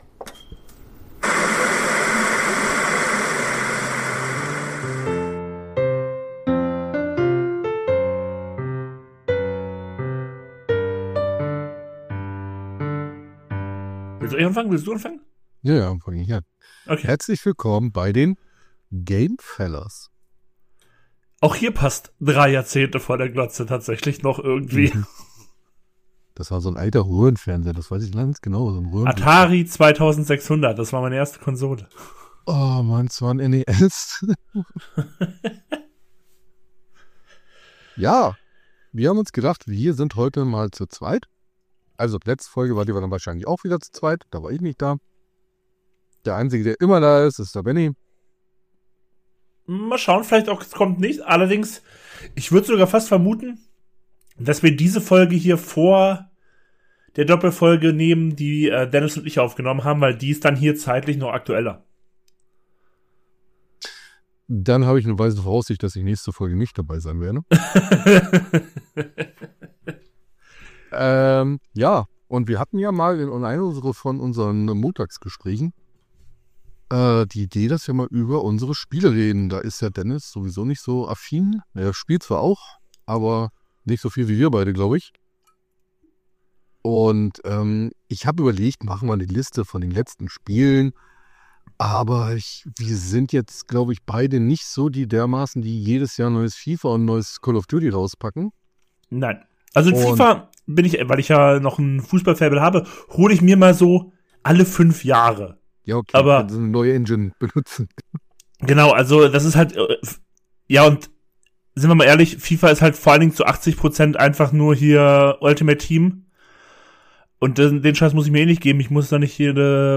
Drei Jahrzehnte vor der Glotze - Diese Zeile beschreibt die drei Filmfellas und ihren Podcast schon ganz gut.